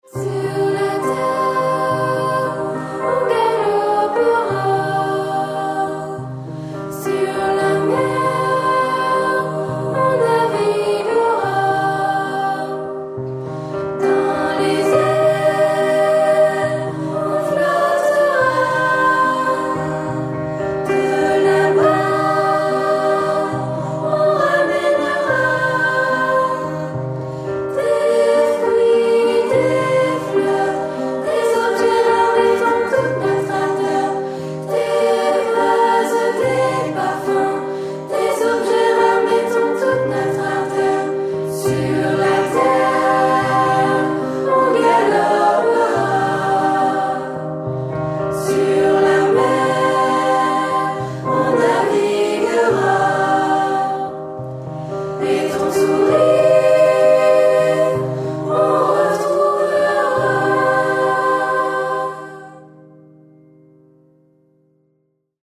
Conte musical et gourmand